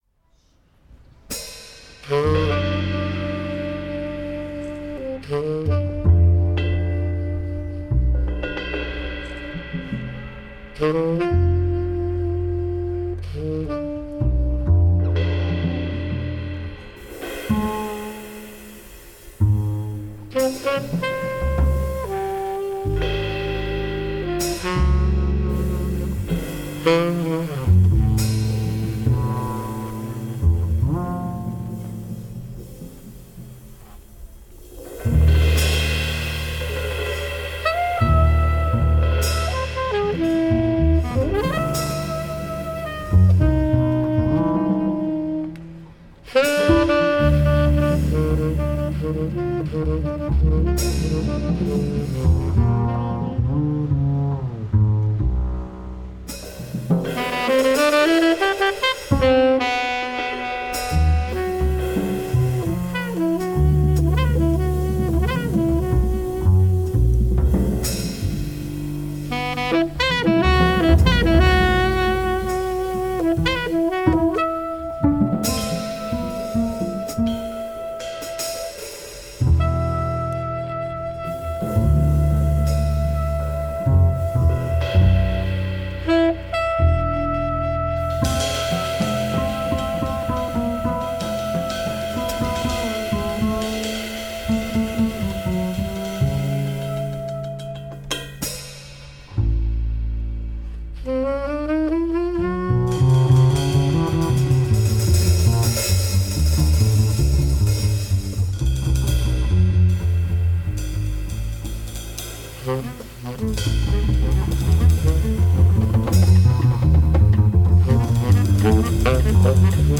tenor sax and clarinet
bass
drums